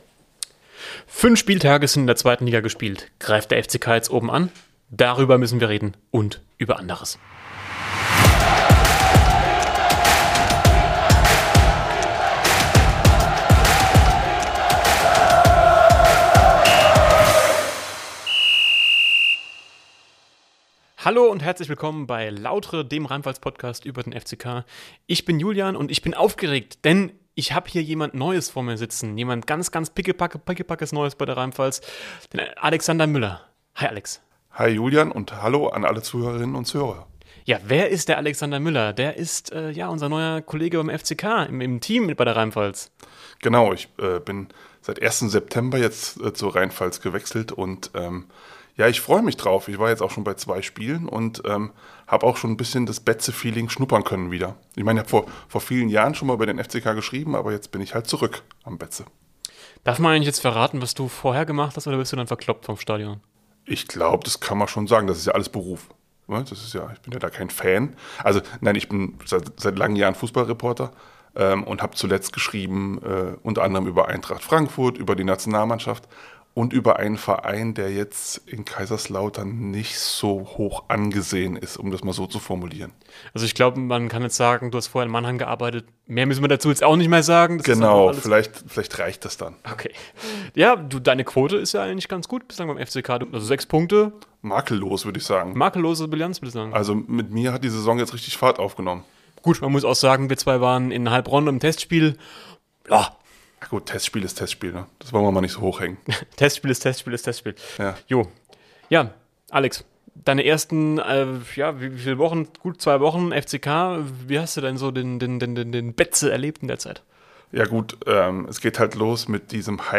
Der Angreifer Mahir Emreli, beim 3:0 in Fürth nicht im Kader, sorgt derzeit mit einem Instagram-Post für Diskussionen unter den Fans. Darüber sprechen die beiden RHEINPFALZ-Redakteure in dieser Folge von "Lautre". Es geht außerdem um die verschwundenen Offensiv-Probleme und die frühen Trainerentlassungen im deutschen Oberhaus und in Liga Zwei.